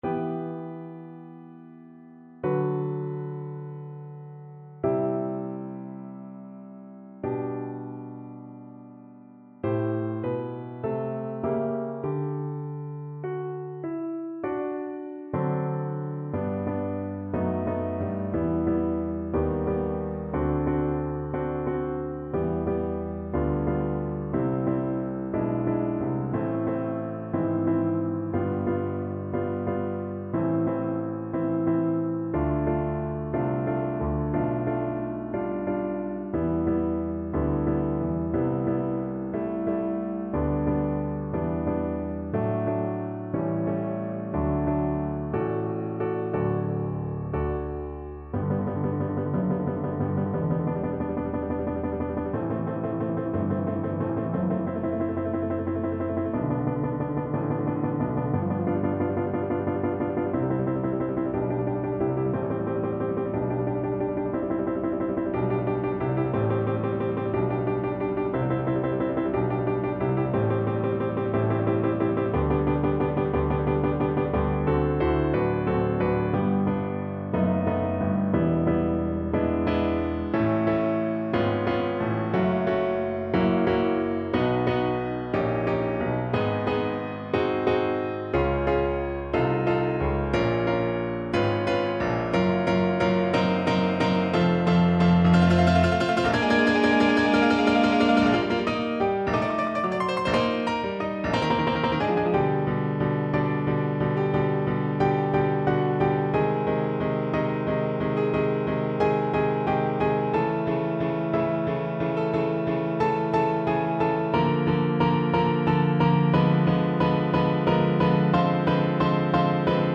Moderato assai
4/4 (View more 4/4 Music)
Classical (View more Classical Viola Music)